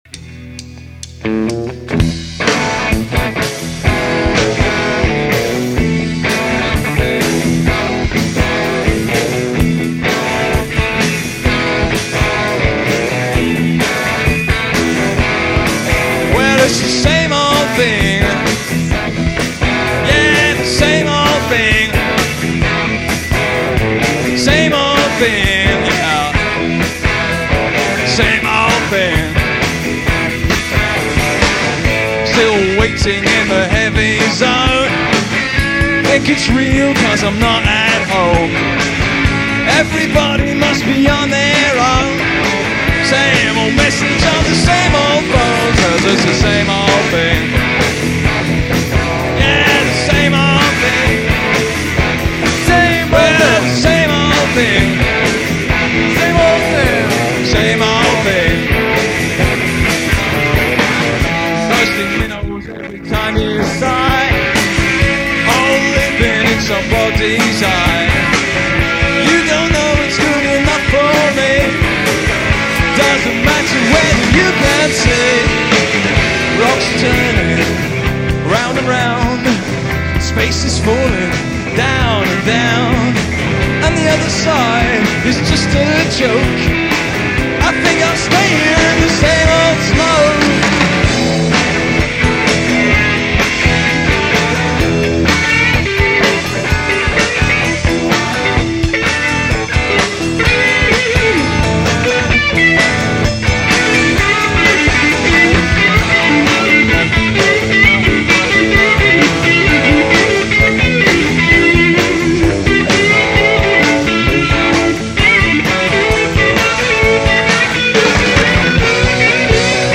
recorded live
guitar, vocal
bass
Highlights of three full-length (45min) gigs.